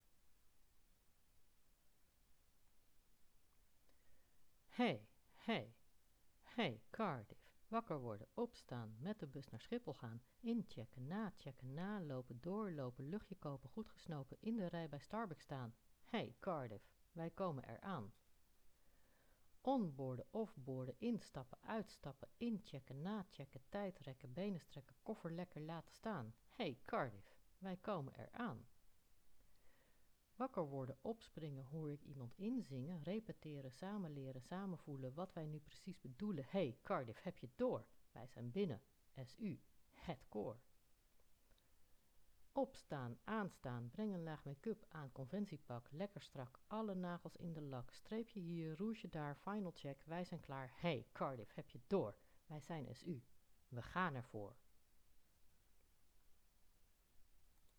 Wél een rappe tekst ook volgend jaar bruikbaar, met andere plaatsnaam.